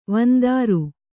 ♪ vandāru